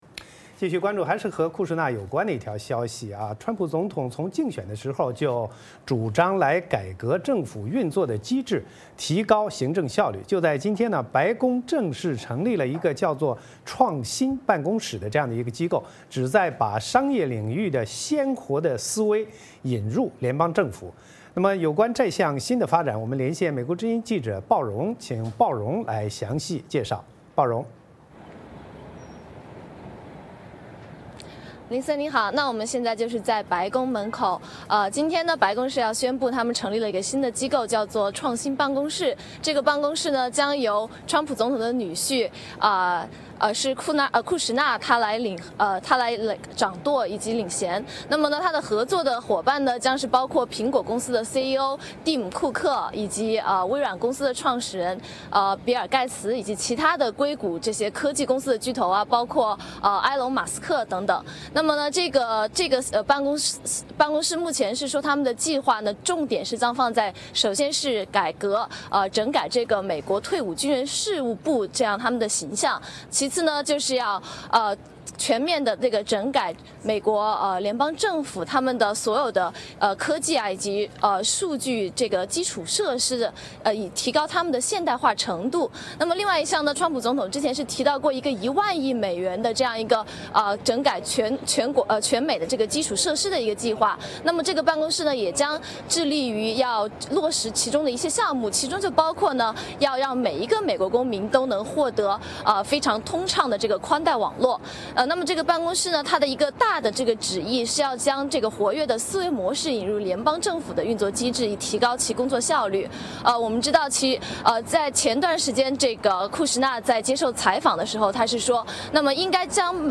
VOA连线: 川普女婿库什纳掌舵白宫创新办公室